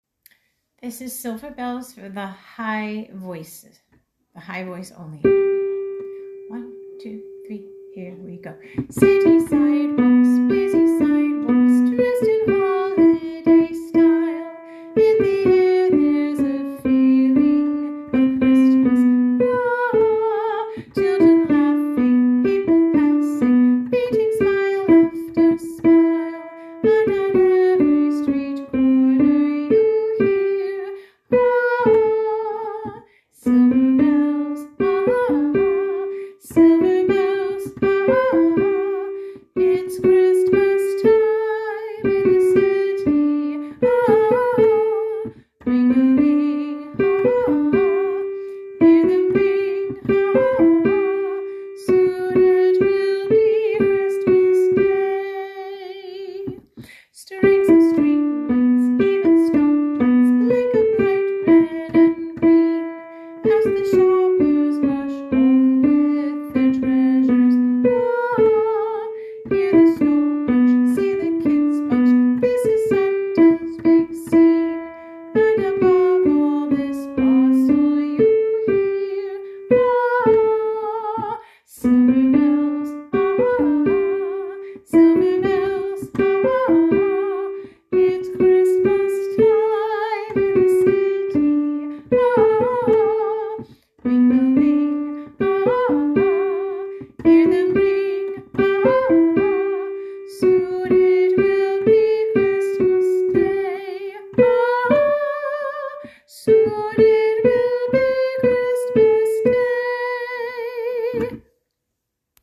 Harmony sing along tracks
high voice